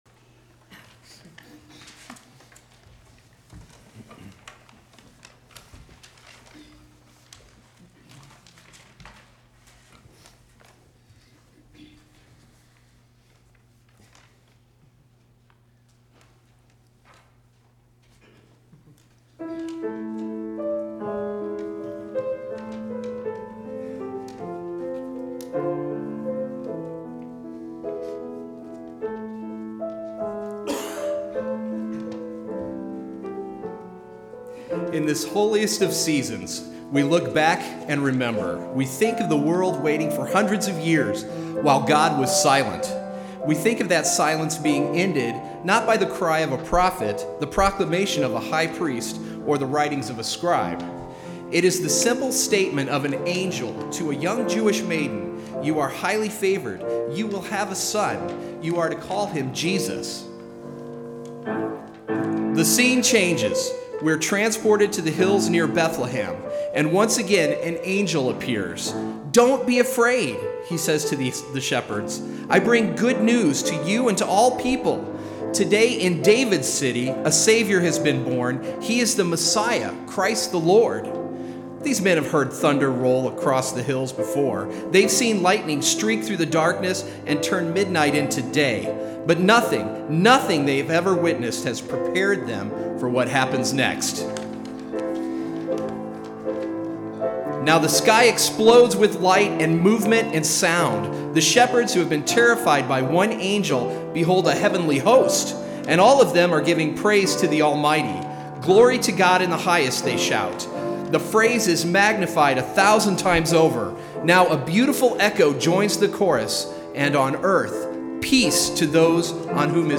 Springfield Bible Church Christmas choir for special